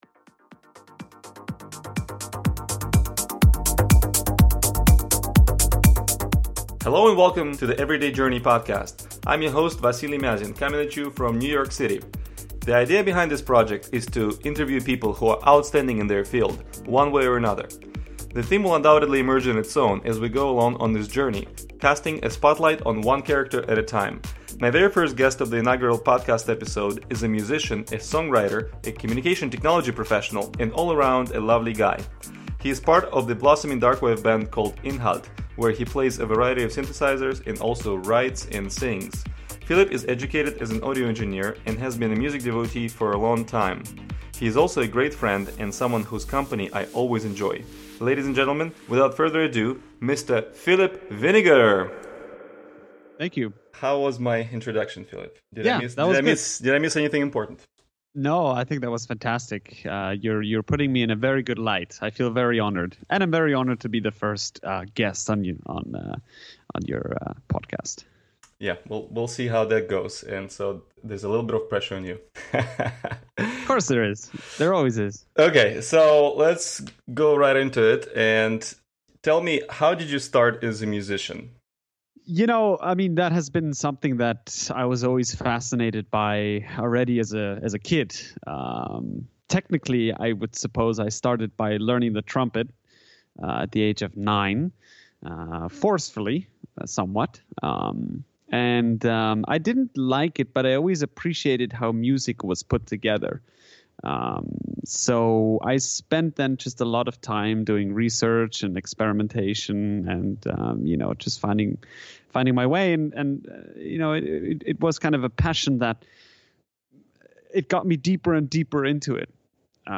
Informal interviews with outstanding people